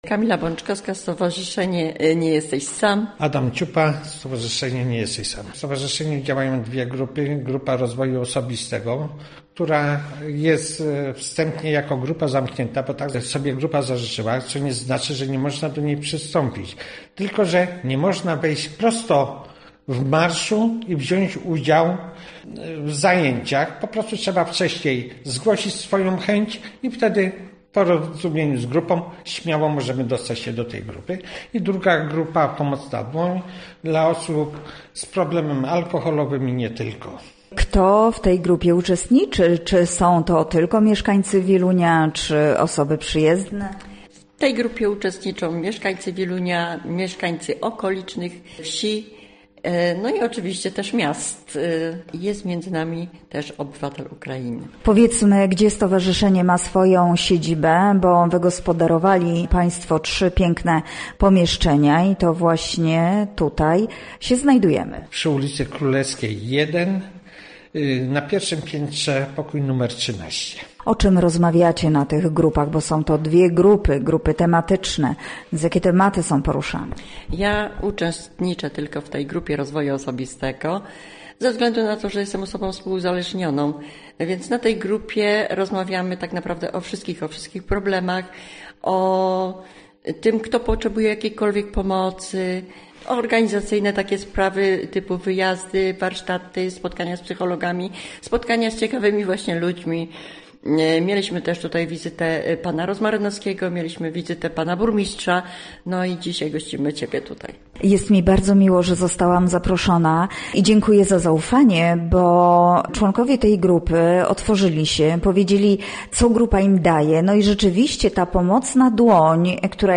Nasi goście mówią o pracy w stowarzyszeniu oraz o celach i zadaniach, jakie sobie stawiają.